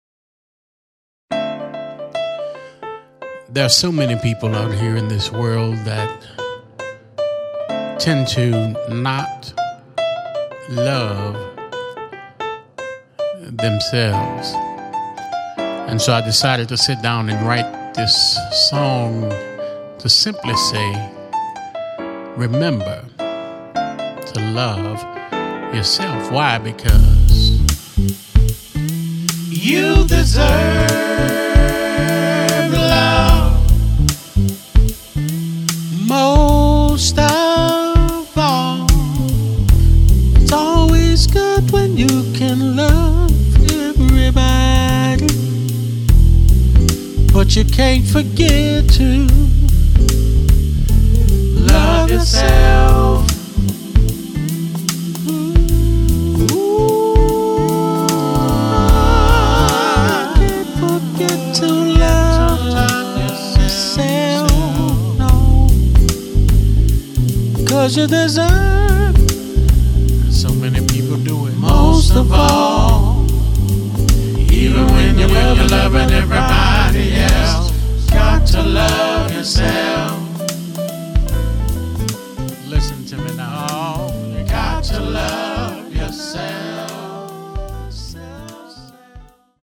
INSPIRATIONAL JAZZ / FUNK